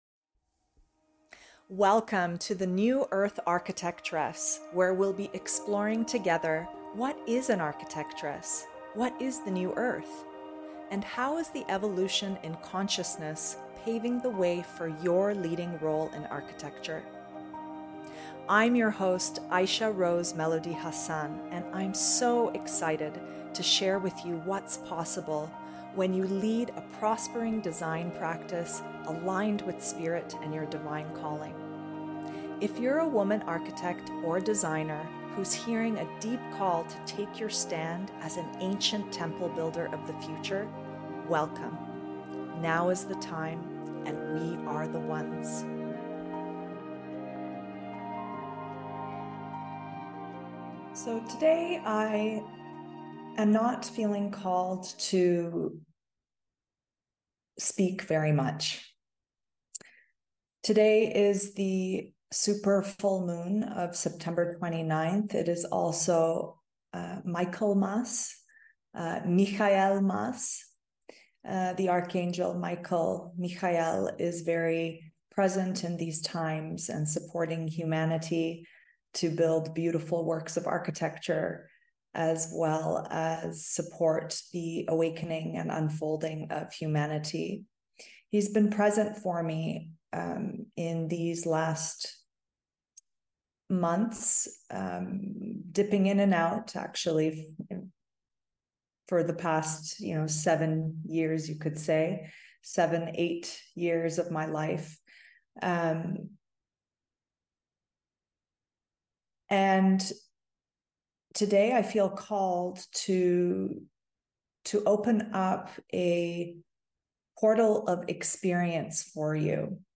Super Moon Light Language Sound Transmission for Architects of the New Earth!
09:09 - Transmission of light language